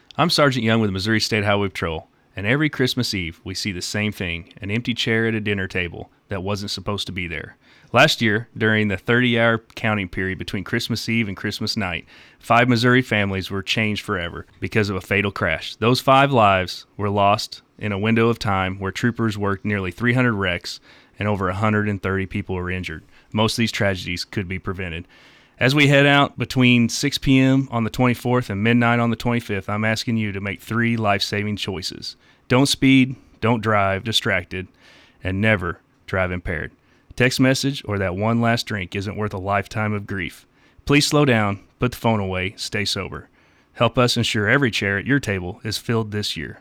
MSHP-Christmas-PSA.wav